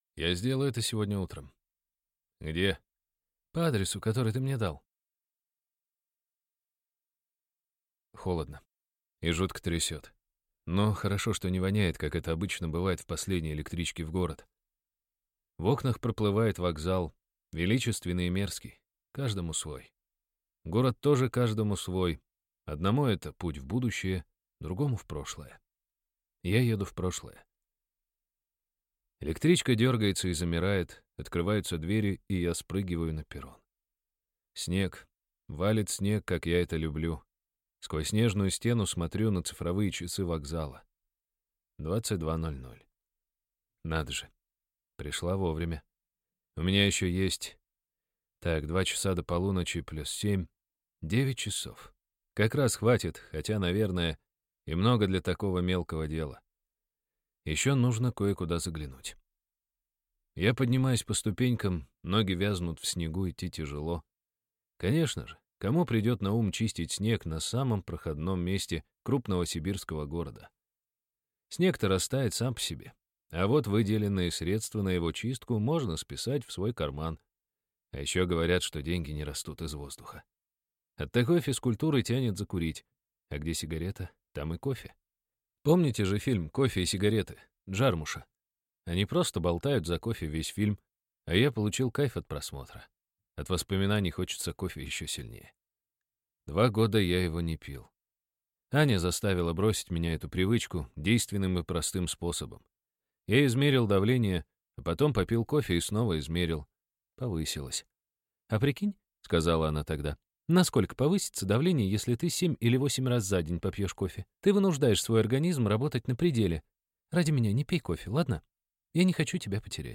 Аудиокнига Этой ночью шел снег | Библиотека аудиокниг